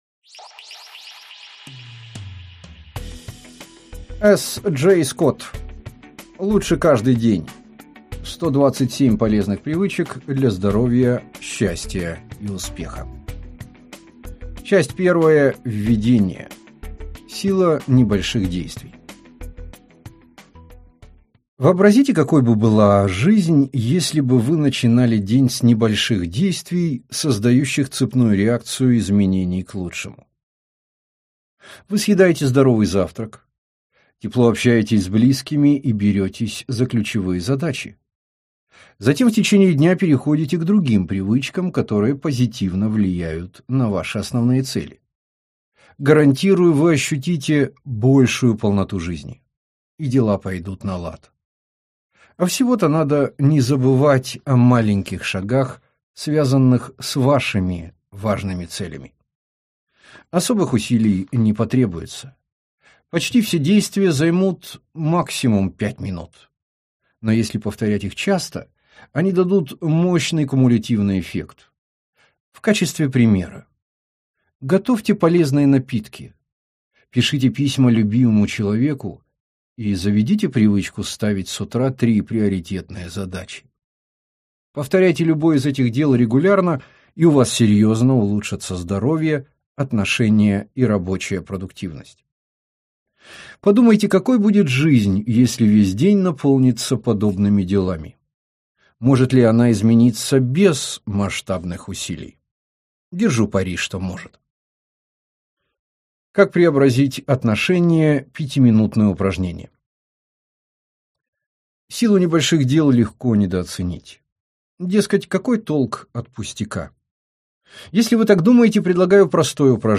Аудиокнига Лучше каждый день: 127 полезных привычек для здоровья, счастья и успеха | Библиотека аудиокниг